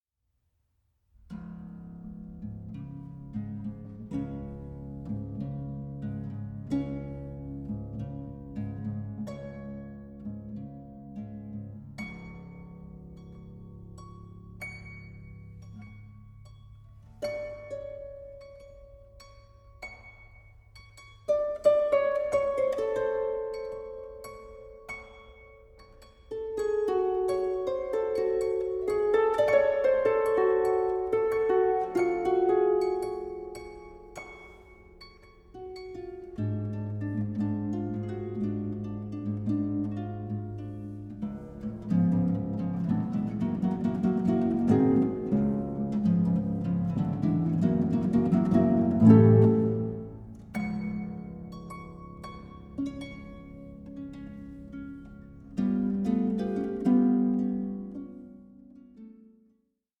for harp